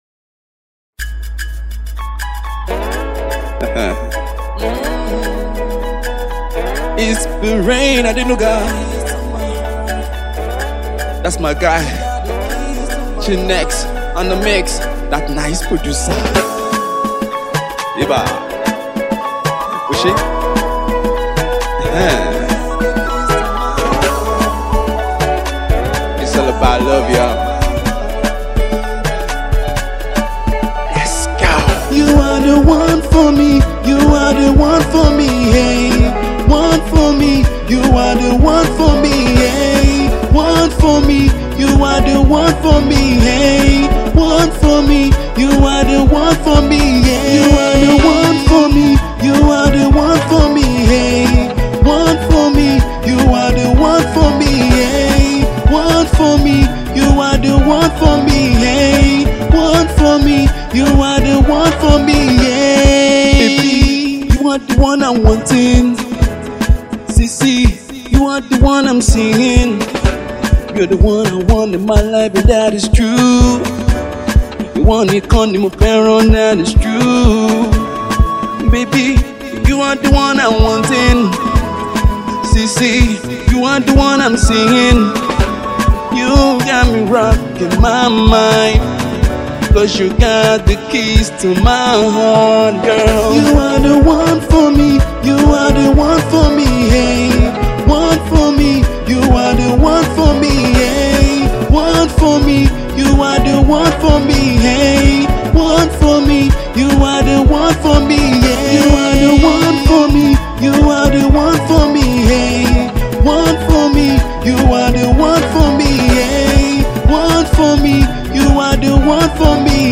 Afro R&B